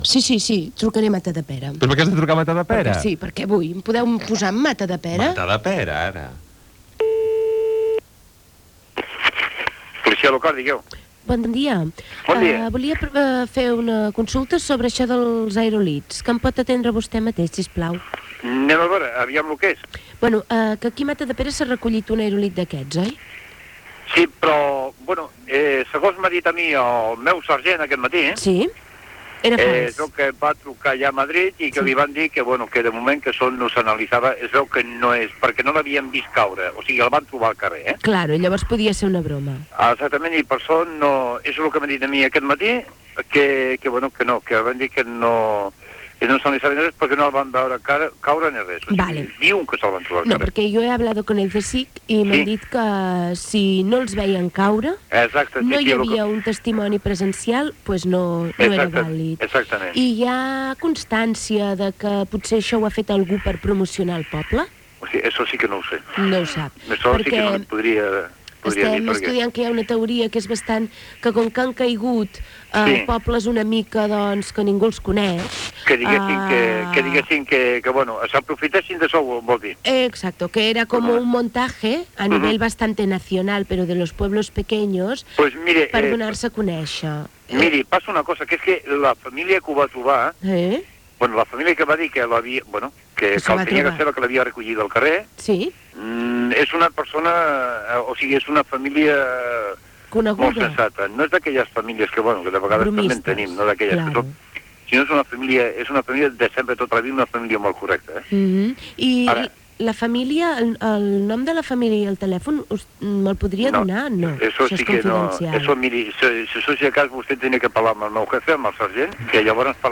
Trucada a la policia local de Matadepera on s'ha recollit un suposat aerolit
Entreteniment